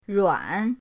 ruan3.mp3